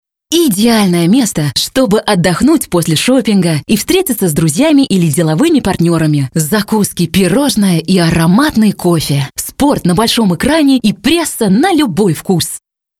Native speaker Female 20-30 lat
demo - język rosyjski